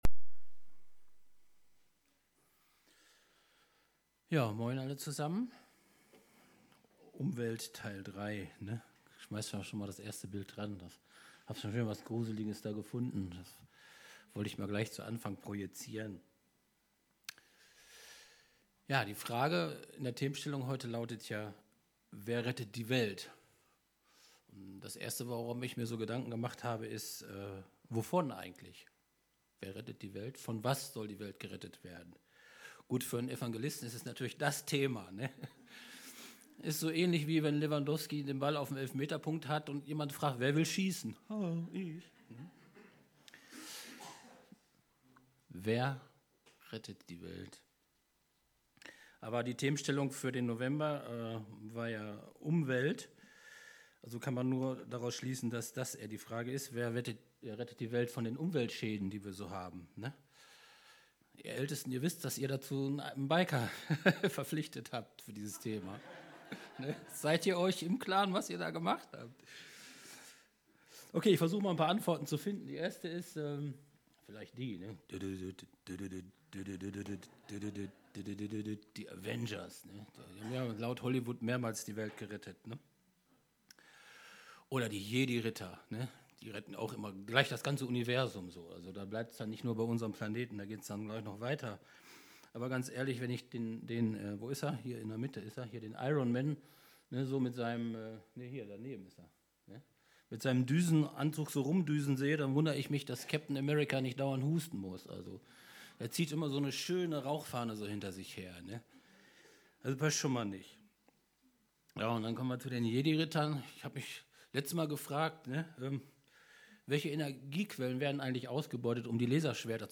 Predigt vom 17.